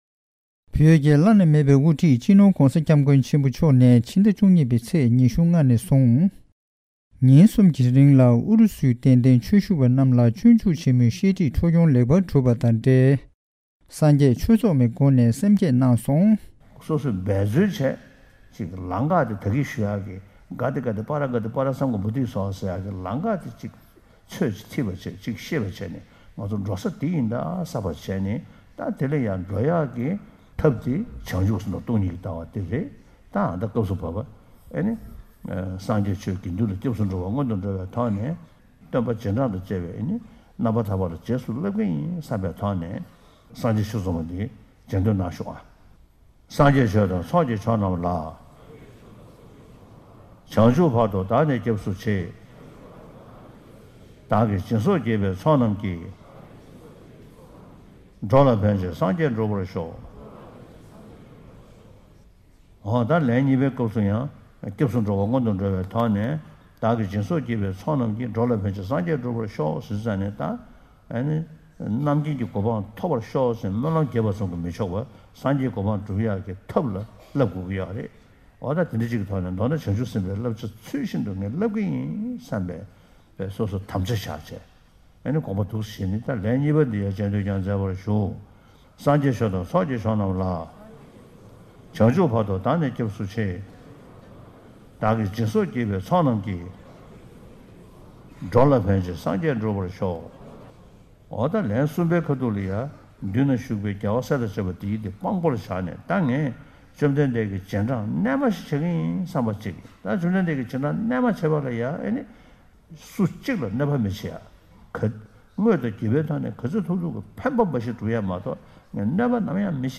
ལྡི་ལི་ནས་བཏང་བའི་གནས་ཚུལ་ཞིག